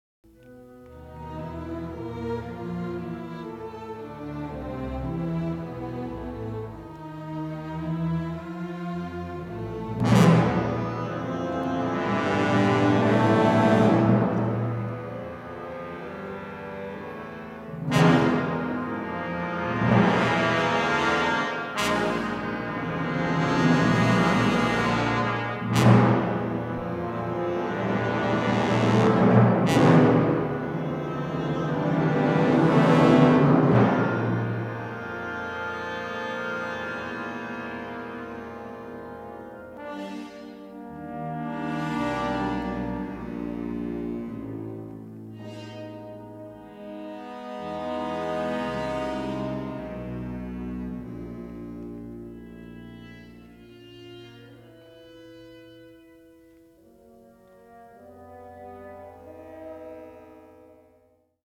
Analog Multi-Track Stereo Remix